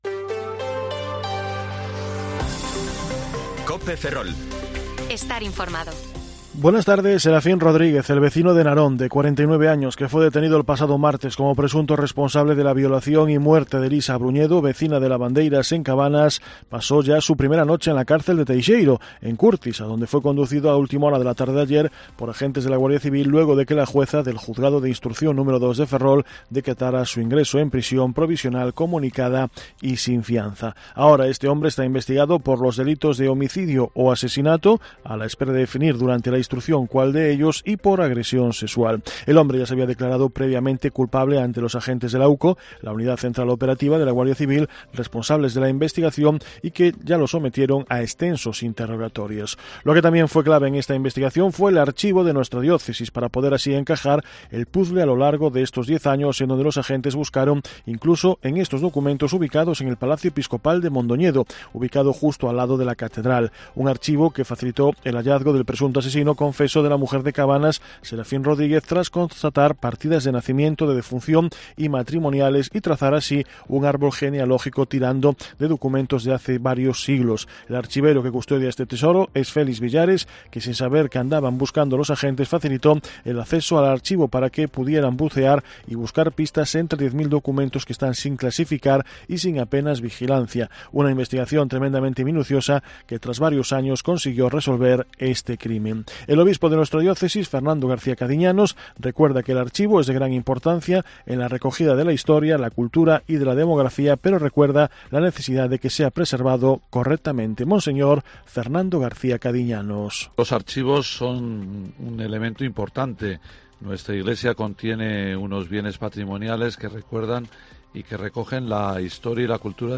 Informativo Mediodía COPE Ferrol 20/10/2023 (De 14,20 a 14,30 horas)